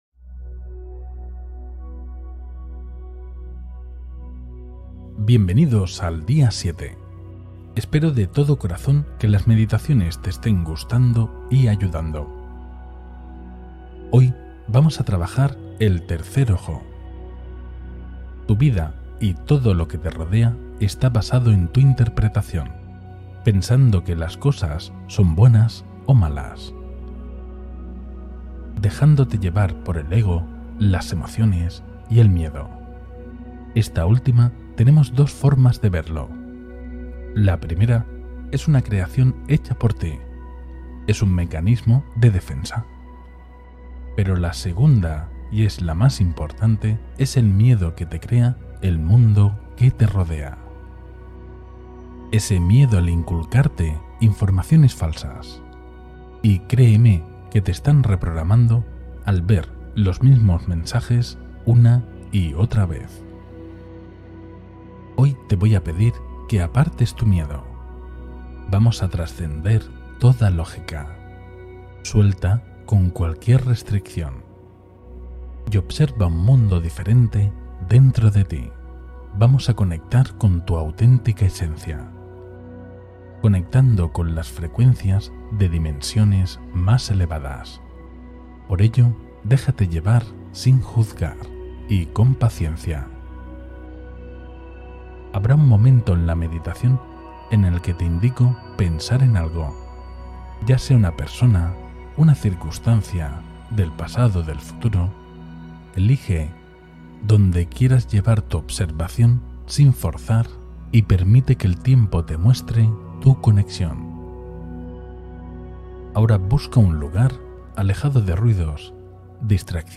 Despierta tu tercer ojo y potencia tu percepción con esta meditación guiada